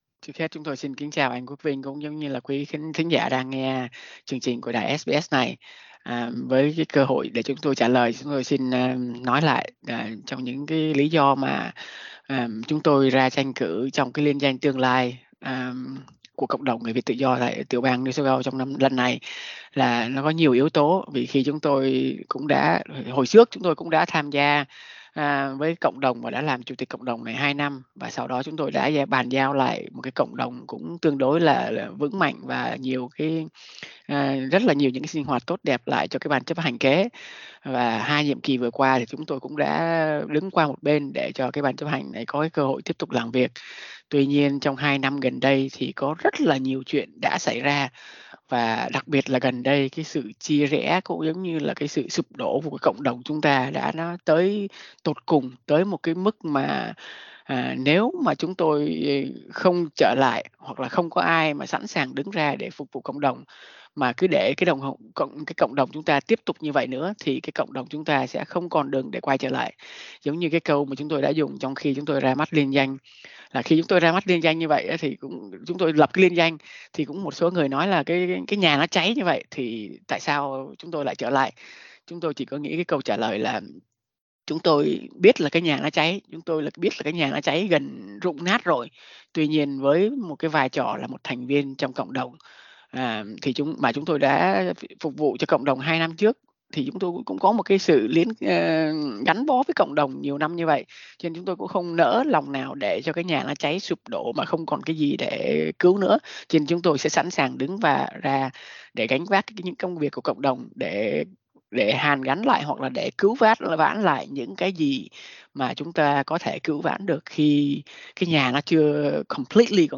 Bầu cử VCA NSW: Phỏng vấn